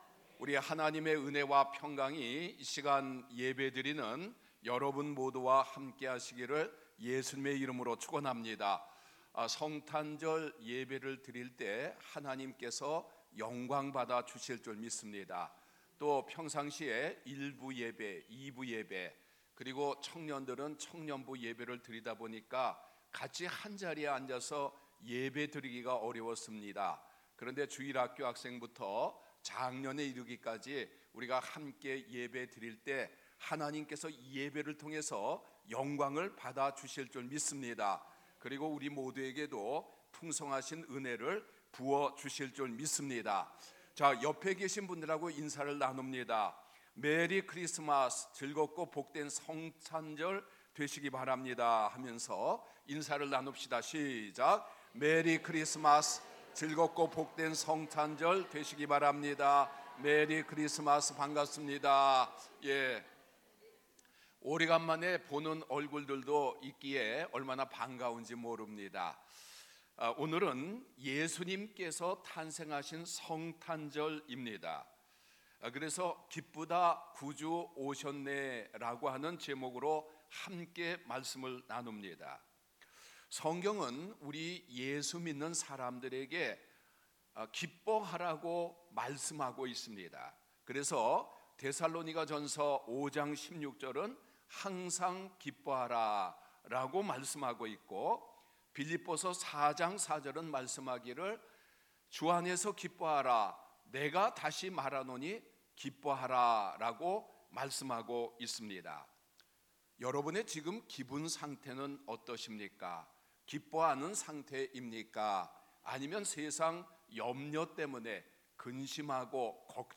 주일설교